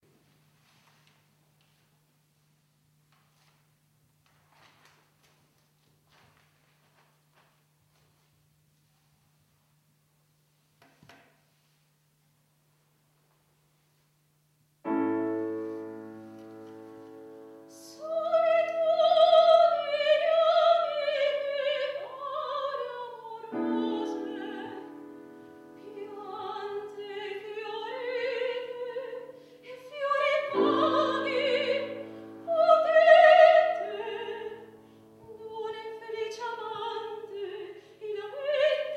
17 ottobre 2009 - II OTTOBRE MUSICALE A PALAZZO VALPERGA - Concerto - Arie Italiane